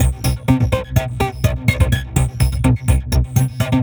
tx_synth_125_crunch_C1.wav